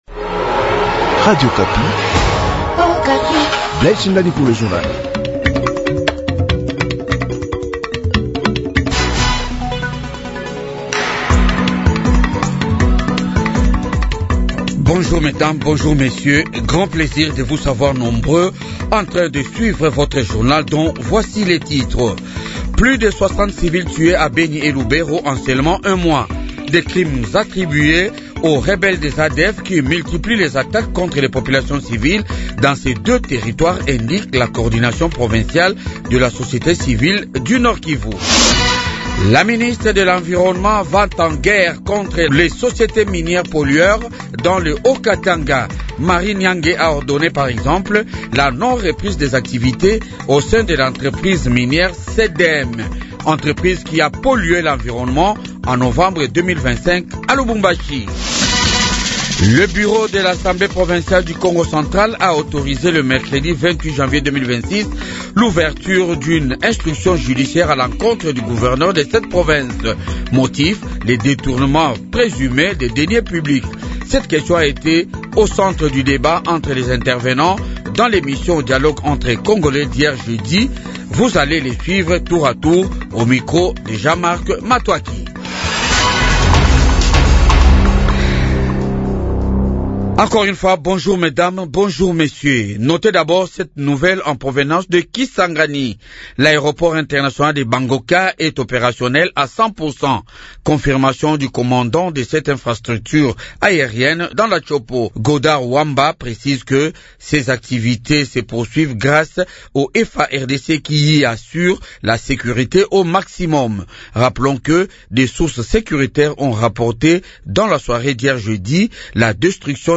Journal du matin 7h